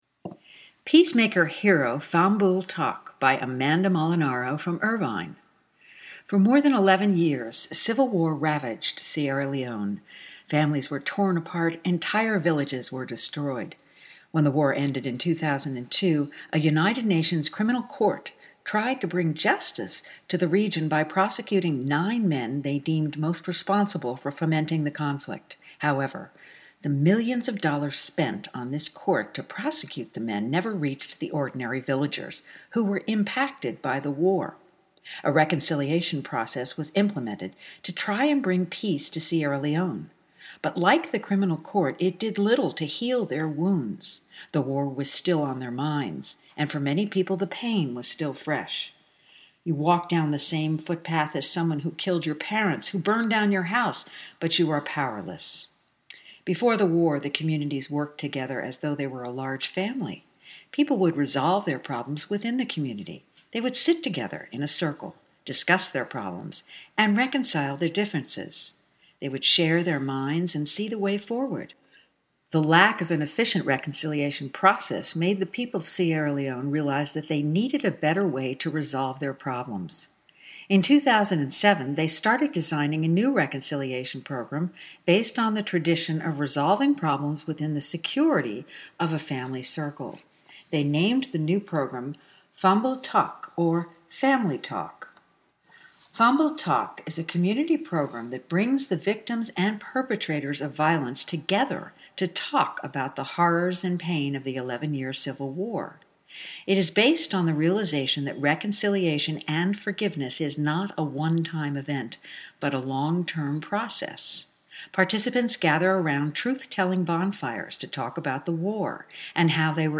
Stories